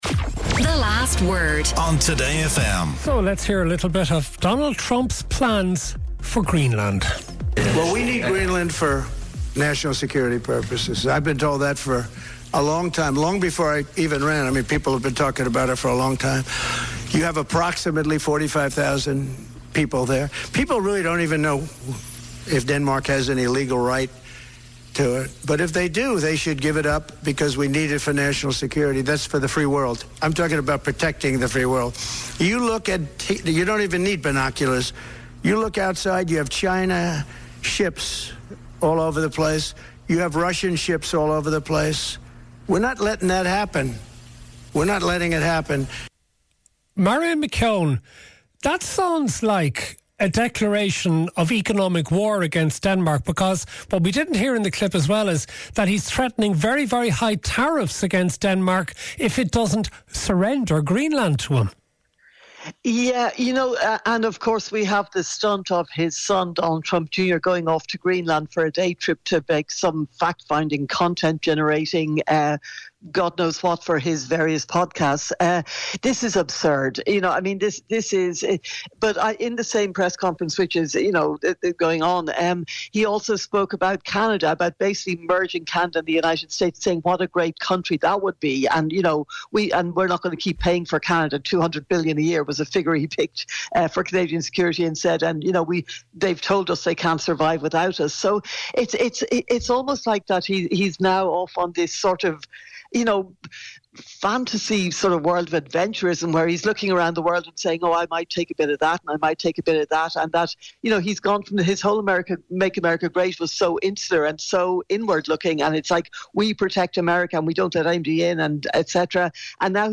Cal Thomas, nationally syndicated US columnist